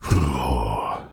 B_hmm3.ogg